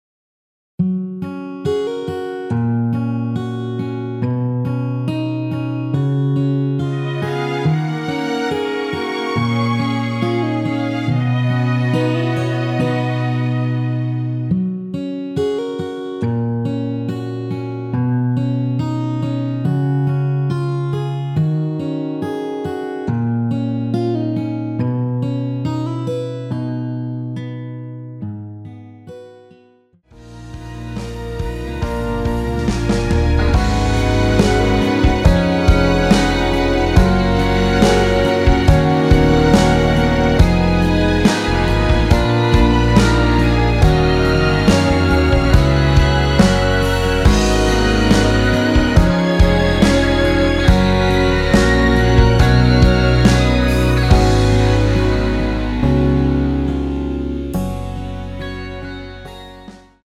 원키에서(+4)올린 MR입니다.(미리듣기 참조)
F#
앞부분30초, 뒷부분30초씩 편집해서 올려 드리고 있습니다.
중간에 음이 끈어지고 다시 나오는 이유는